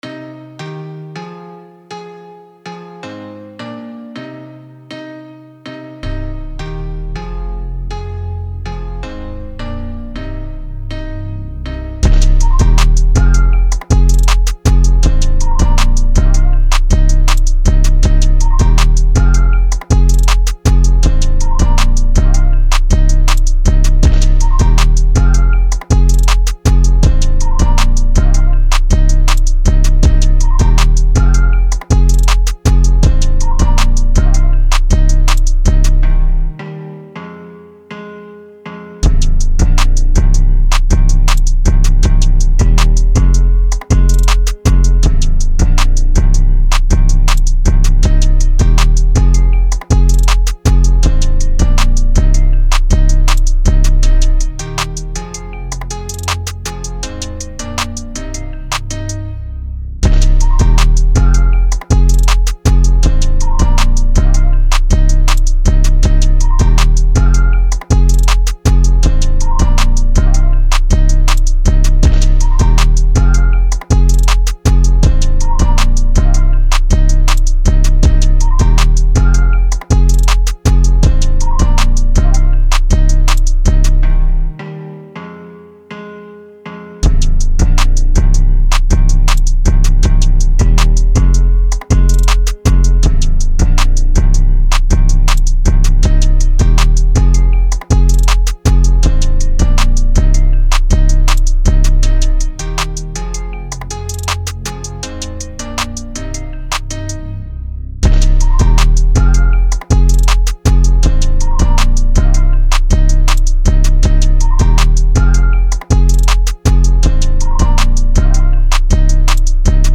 R&B, Hip Hop
C Min